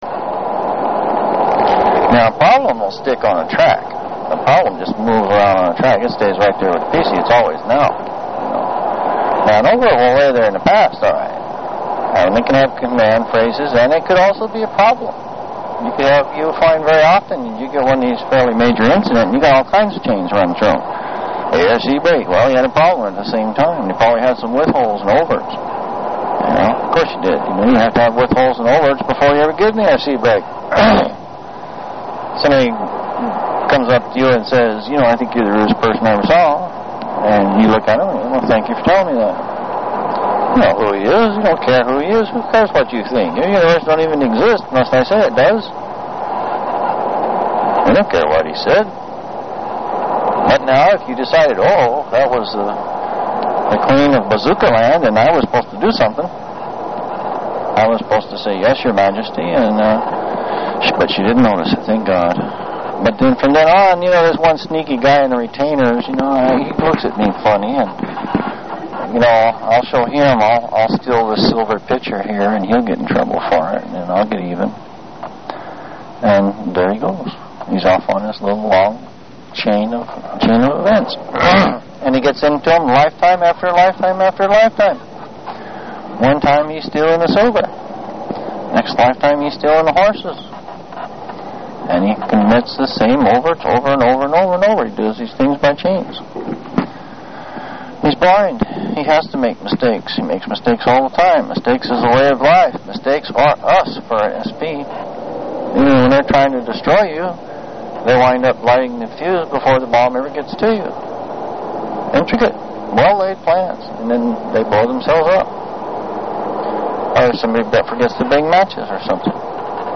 This seems to be the lowest size with decent quality.
These mp3 lectures can be downloaded overnight or something if you have a slow modem, but the quality is much better.